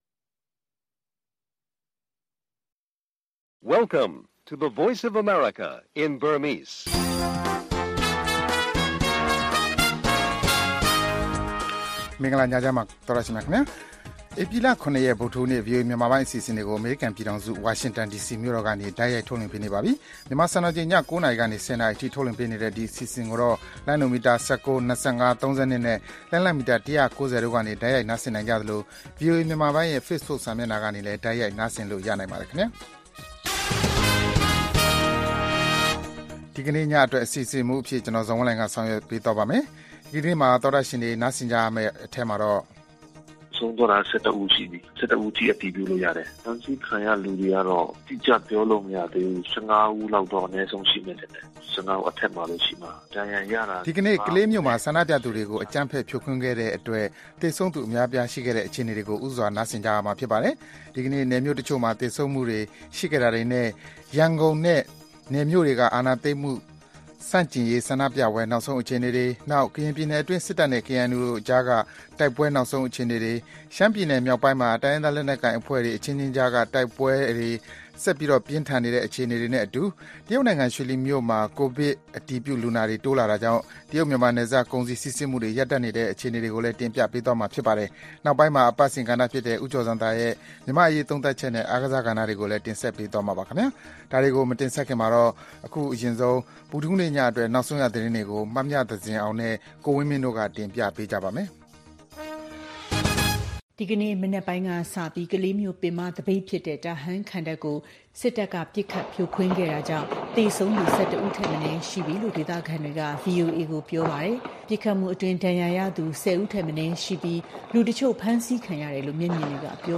ဆန္ဒပြပွဲတွေ ပစ်ခတ်ဖြိုခွဲခံရလို့ ထိခိုက်သေဆုံးတဲ့အခြေအနေများနဲ့အတူ မြန်မာ့အရေးသုံးသပ်ချက်နဲ့ အားကစား သီတင်းပတ်စဉ်ကဏ္ဍတွေအပါအဝင် ဗုဒ္ဓဟူးည ၉း၀၀-၁၀း၀၀ နာရီ ရေဒီယိုအစီအစဉ်